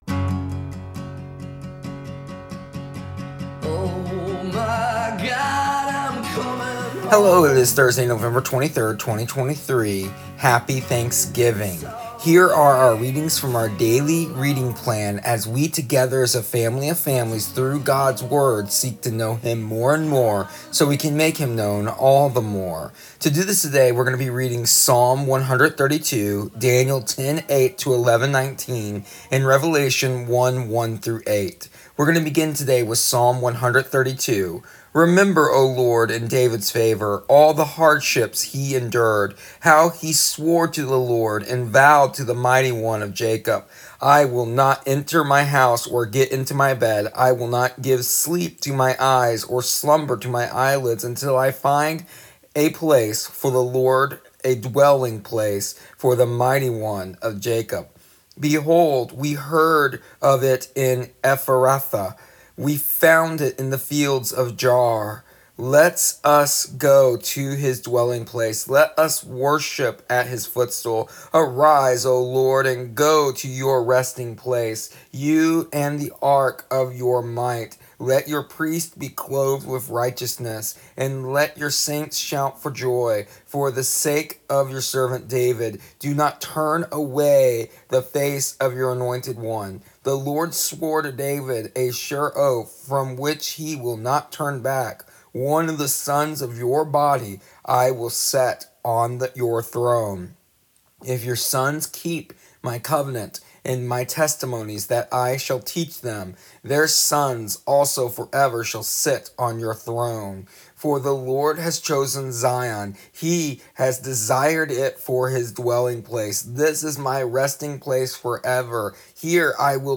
Here is the audio version of our daily readings from our daily reading plan Knowing Him for November 23rd, 2023. Just a bit of commentary as we begin our reading of Revelation today.